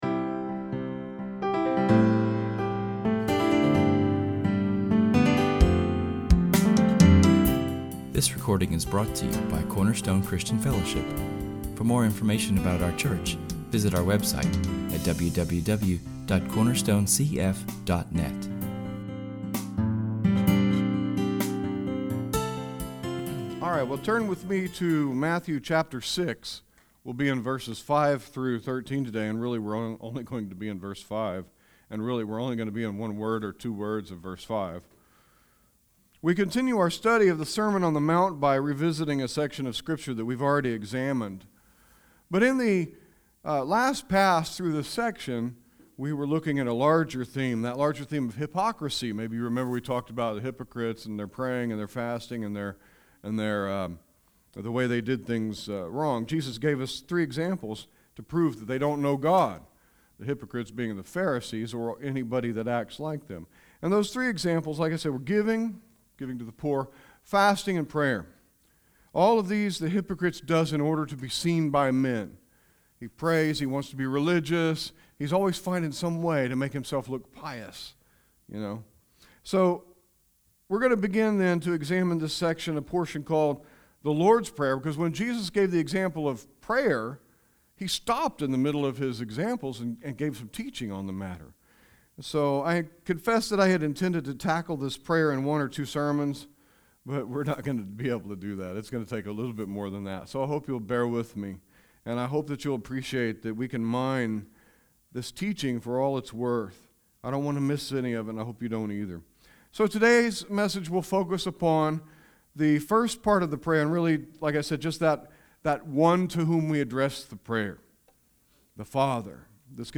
Our sermon this week will be entitled The Model Prayer: Our Father.
Public reading: [esvignore]Psalm 30[/esvignore] ; Benediction: [esvignore]Romans 15:13[/esvignore] Matthew 6:5-13 Psalm 30 Romans 15:13